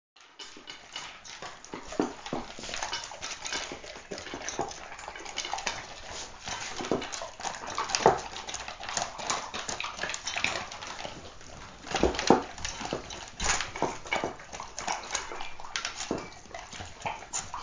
Was das ist? Vielleicht ein x-beliebiger Schweinestall während der Fütterung? Fast...
Unsere Beiden bekommen ihr Futter natürlich gleichzeitig und das ist der Klang von hungrigen Hunden, die aus Edelstahlnäpfen mampfen.